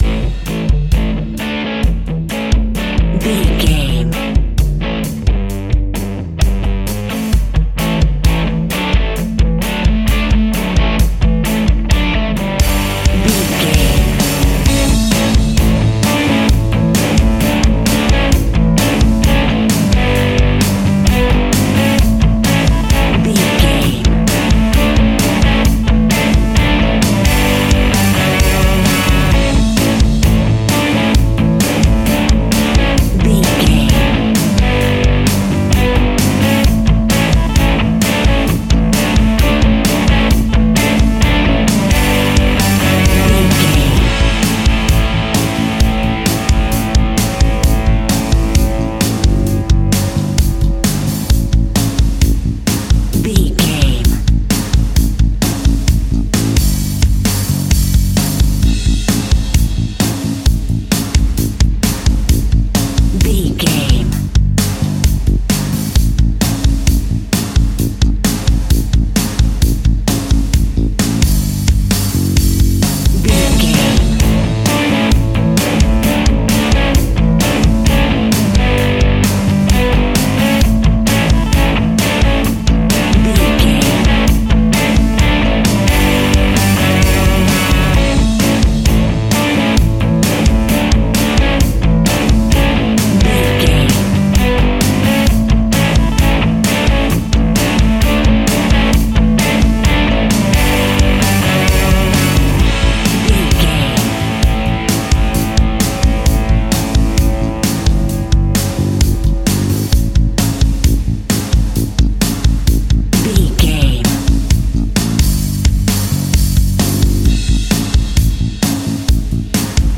Ionian/Major
energetic
driving
aggressive
electric guitar
bass guitar
drums
hard rock
heavy metal
distortion
rock instrumentals
heavy drums
distorted guitars
hammond organ